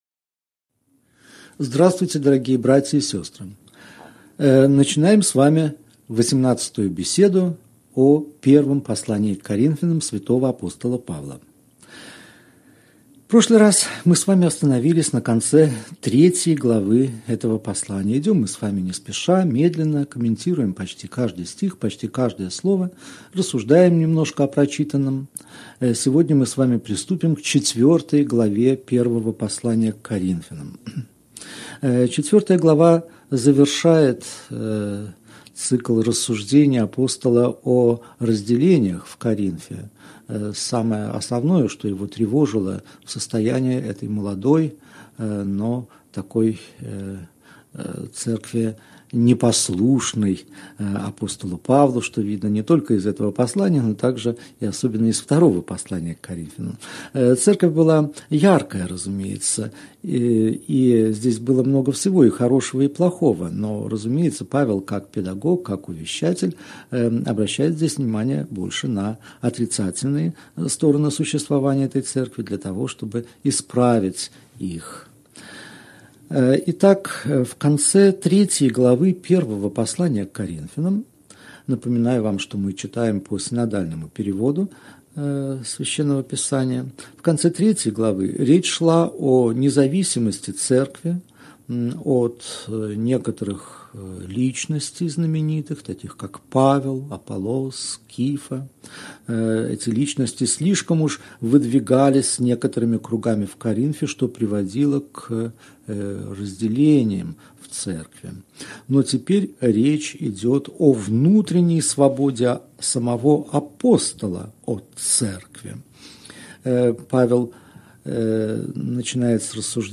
Аудиокнига Беседа 18. Первое послание к Коринфянам. Глава 4 | Библиотека аудиокниг